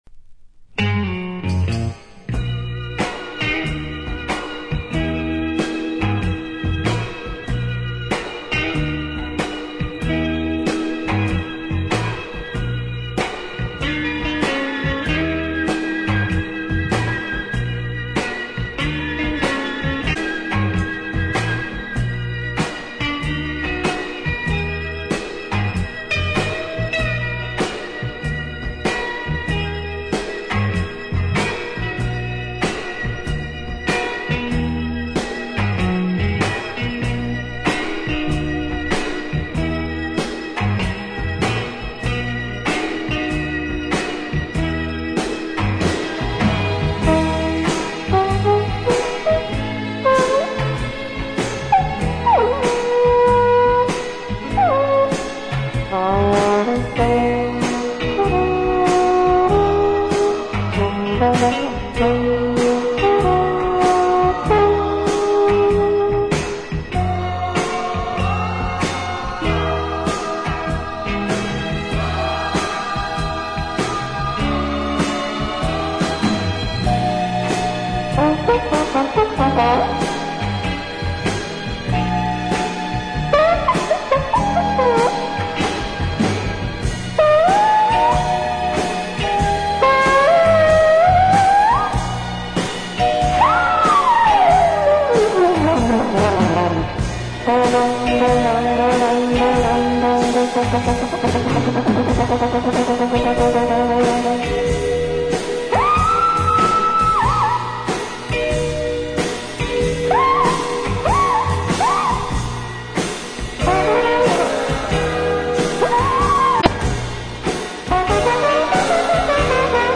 flugelhorn